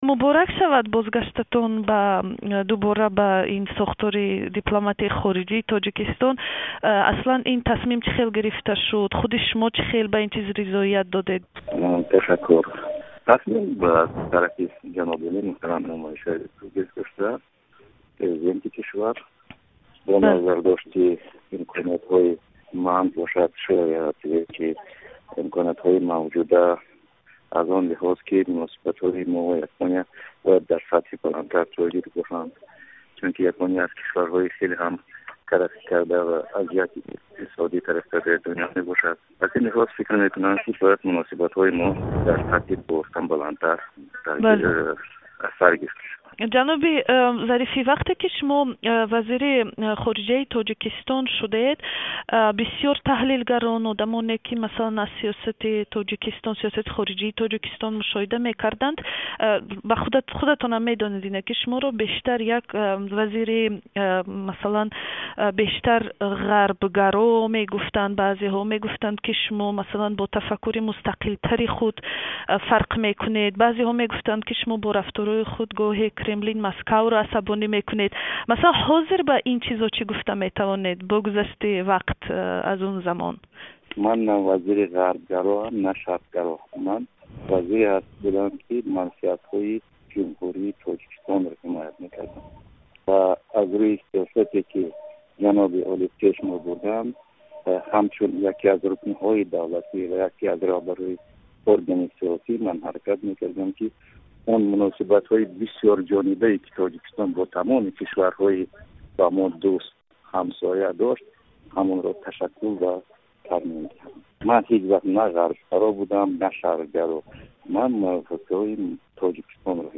Собиқ вазири умури хориҷии Тоҷикистон бо як амри президент ба мақоми сафири Тоҷикистон дар Япония таъин шуд. Ҳамрохон Зарифӣ дар як тамоси телефонӣ аз Душанбе ба саволҳои радиои Озодӣ посух дод